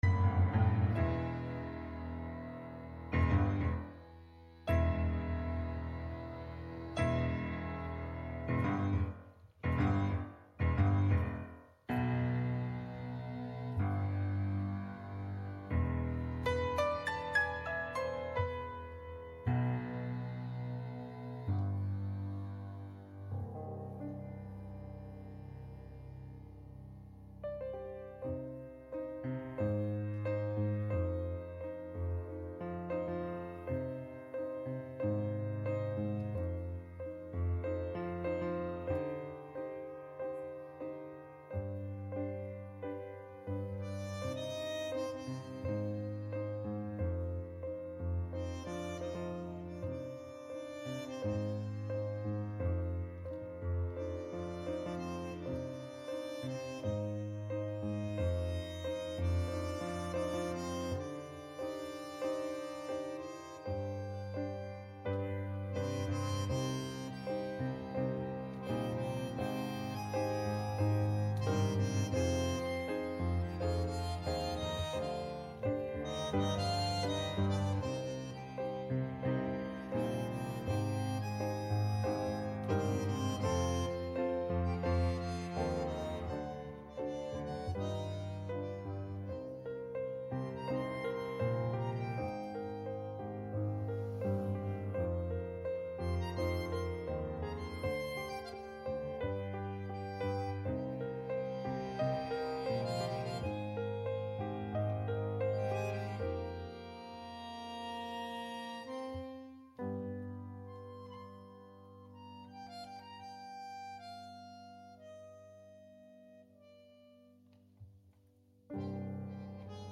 Gottesdienst am 21. Januar 2023 aus der Christuskirche Altona